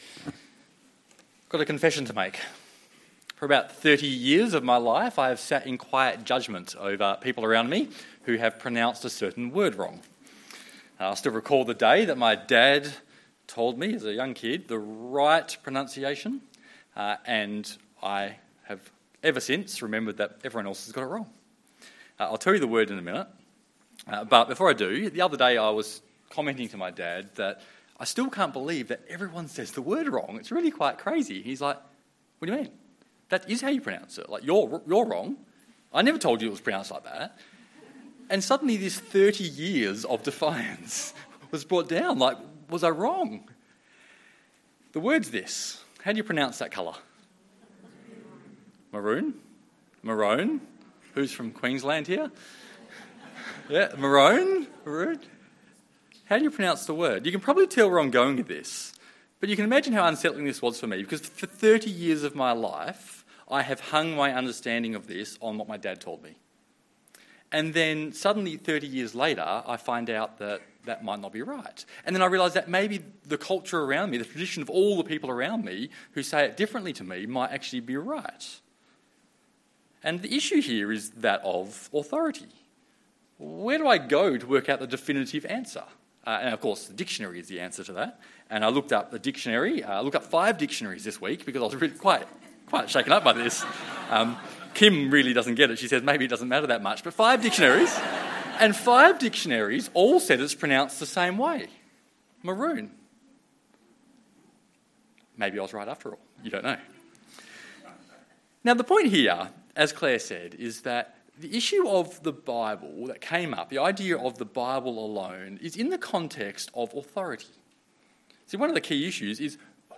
2 Timothy 3:10-17 Service Type: Sunday Evening Download Files Notes Topics: Reformation truths; Solas « In Christ